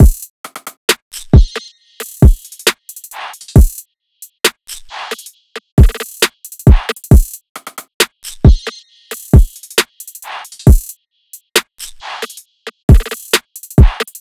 drumloop 10 (135 bpm).wav